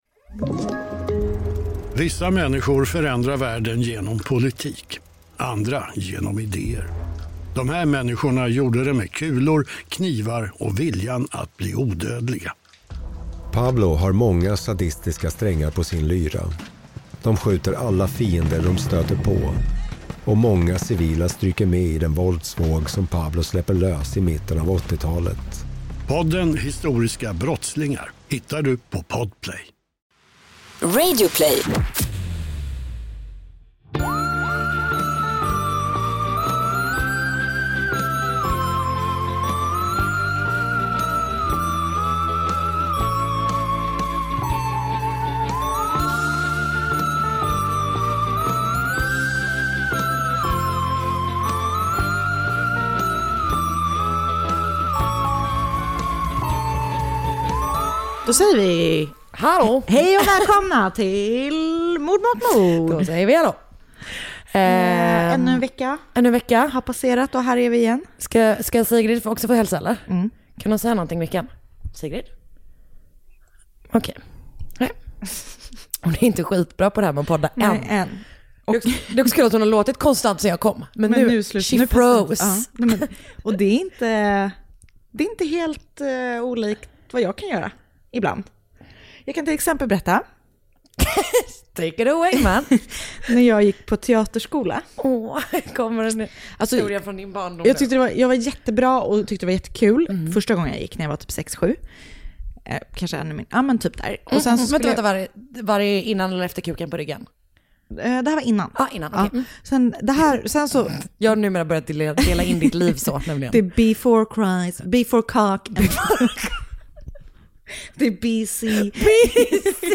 Mord Mot Mord är en vanlig snackig podd, fast om mord. Det är lättsamt prat i ett försök att hantera världens värsta ämne.